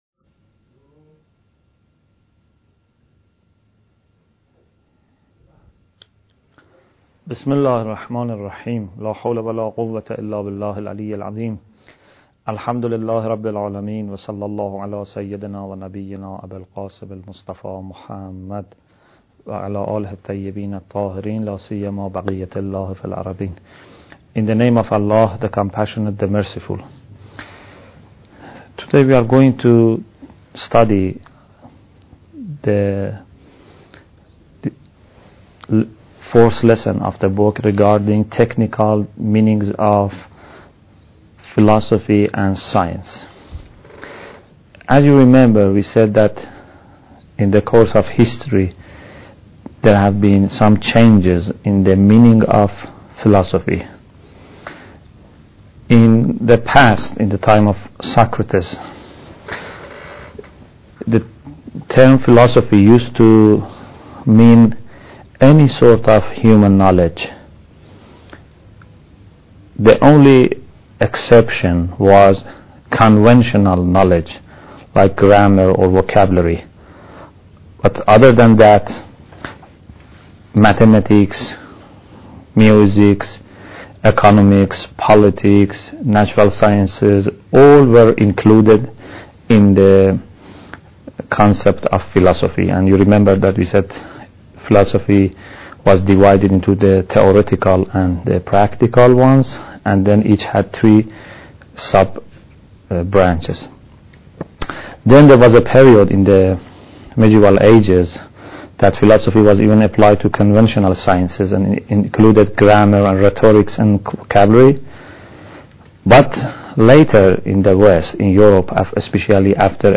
Bidayat Al Hikmah Lecture 7